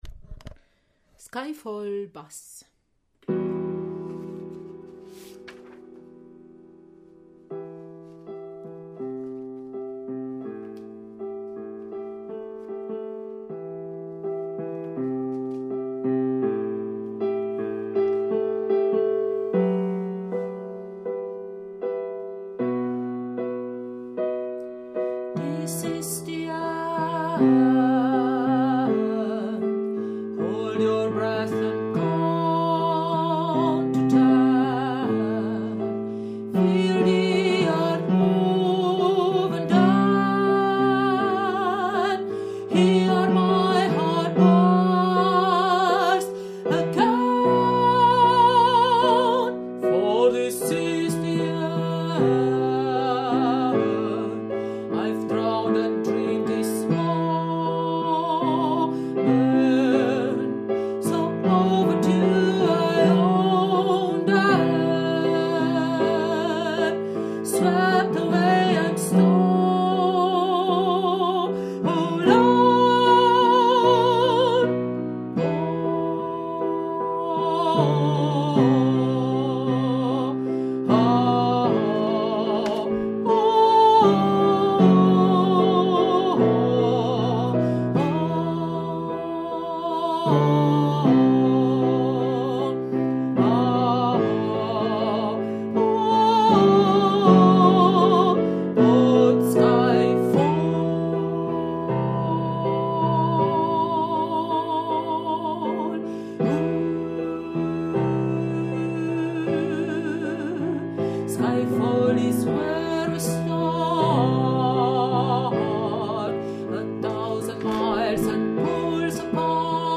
Skyfall-Bass.mp3